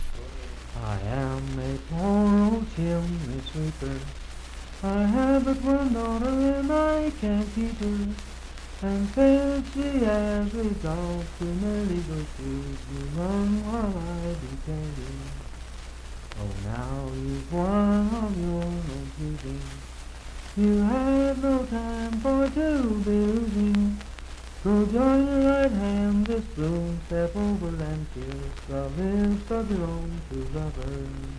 Unaccompanied vocal music
Voice (sung)
Marlinton (W. Va.), Pocahontas County (W. Va.)